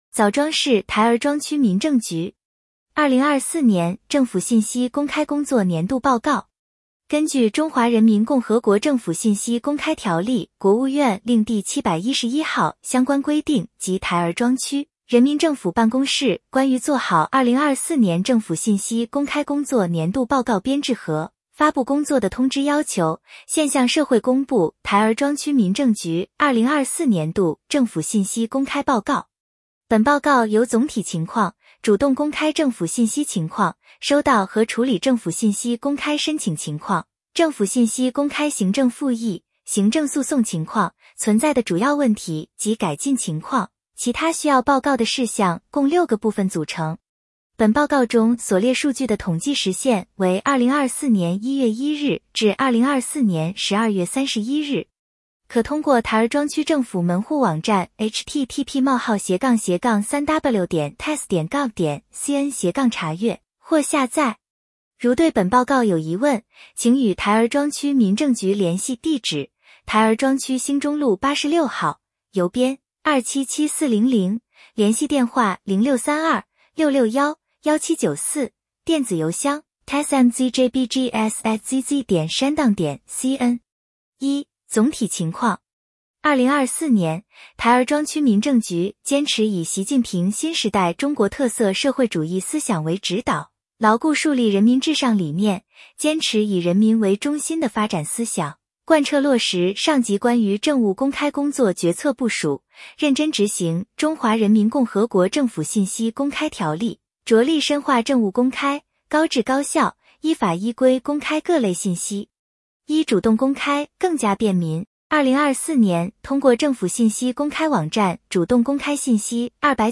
点击接收年报语音朗读 枣庄市台儿庄区民政局2024年政府信息公开工作年度报告 作者： 来自： 时间：2025-01-14 枣庄市台儿庄区民政局 2024年政府信息公开工作年度报告 根据《中华人民共和国政府信息公开条例》（国务院令第711号）相关规定及《台儿庄区人民政府办公室关于做好2024年政府信息公开工作年度报告编制和发布工作的通知》要求，现向社会公布台儿庄区民政局2024年度政府信息公开报告。